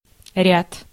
Ääntäminen
Ääntäminen US
IPA : /ˈsikwəns/